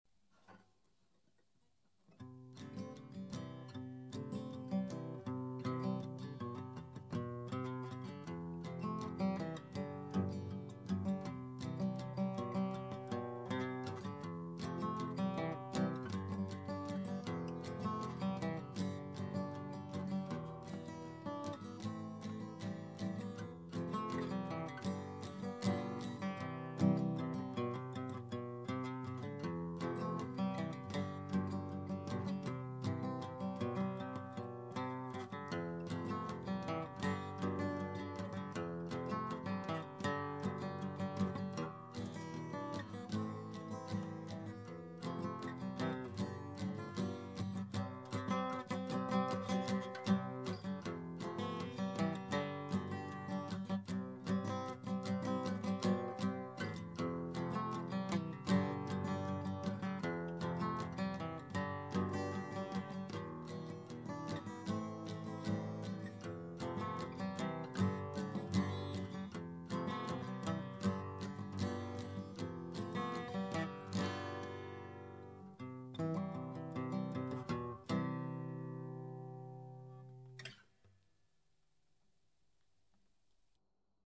Instrumentals
A chordal expression of love between a man and his Antoria guitar. Unbelievably quiet recording, a problem which we have failed to overcome.